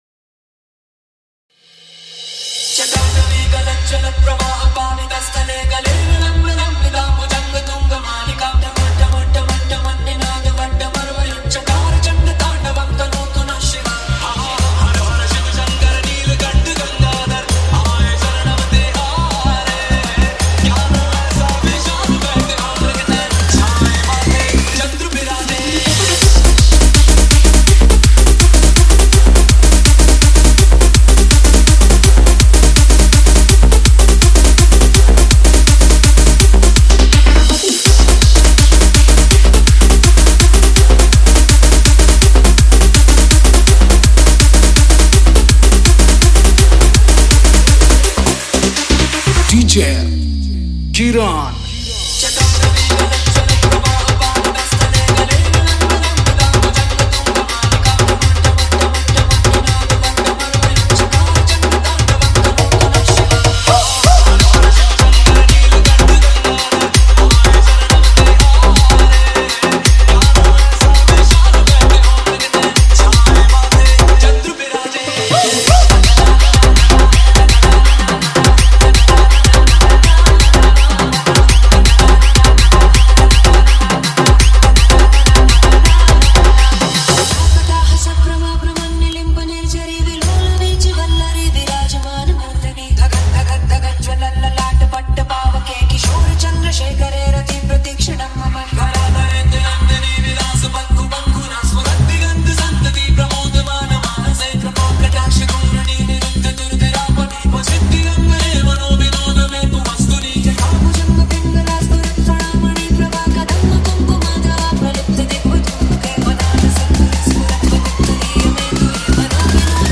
Bolbum Special Dj Song